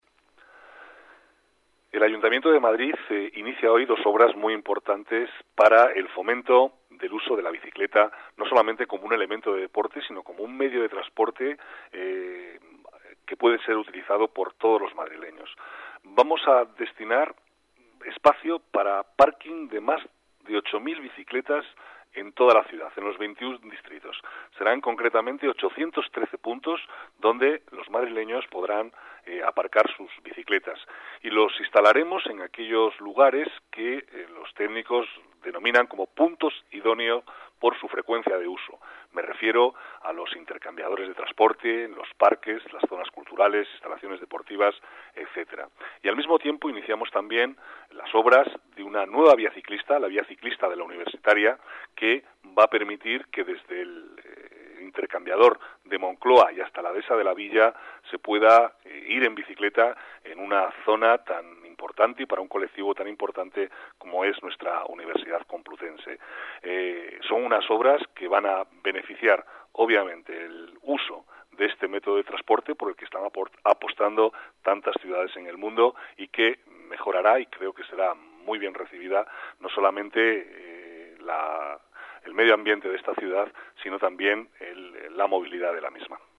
Nueva ventana:Declaraciones de Manuel Cobo